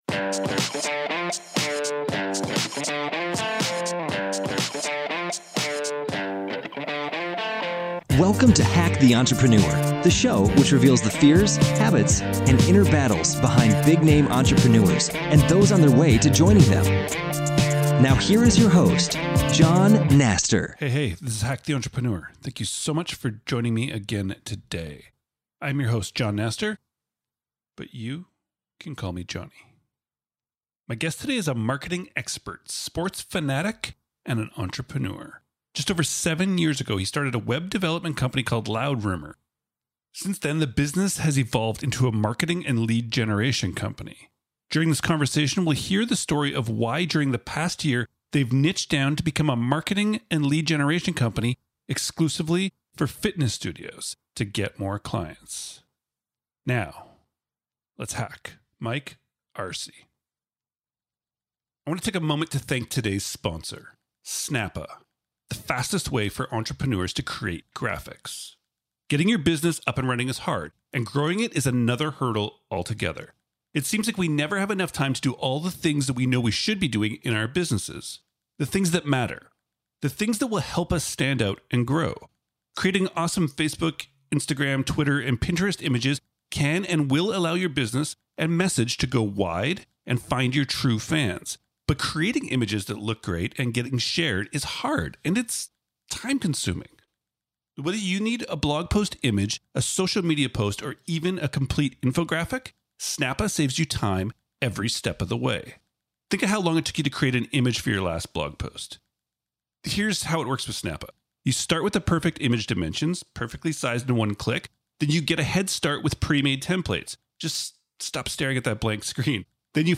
My guest today is a marketing expert, sports fan, and entrepreneur.